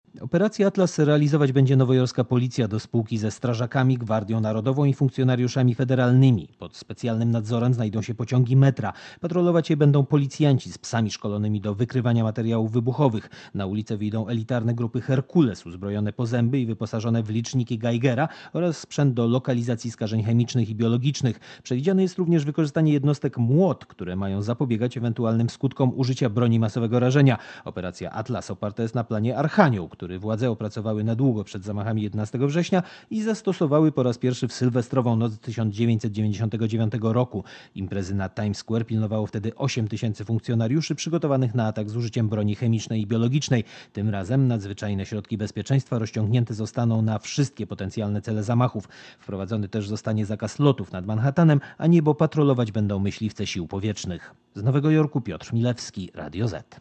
Z Nowego Jorku mówi korespondent Radia Zet (443 KB)